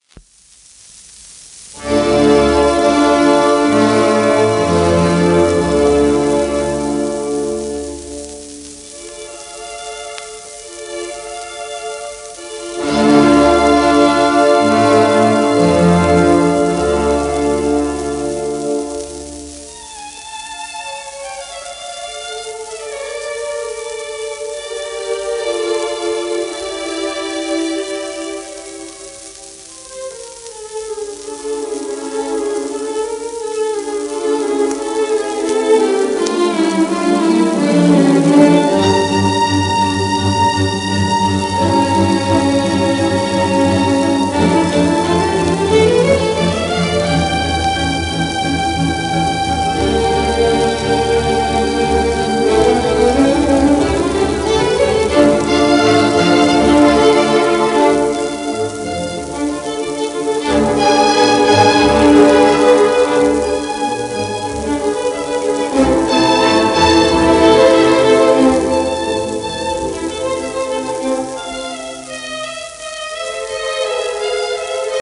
1935年録音